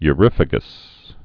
(y-rĭfə-gəs)